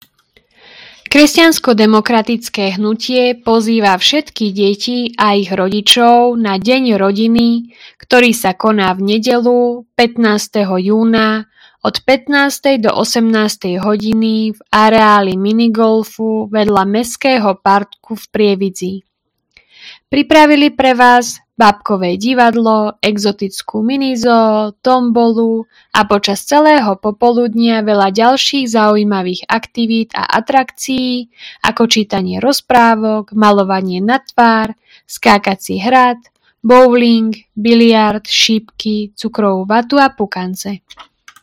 Hlásenie obecného rozhlasu – Deň rodiny 15.06.2025 v Prievidzi